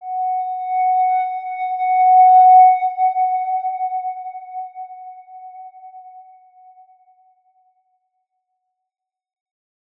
X_Windwistle-F#4-pp.wav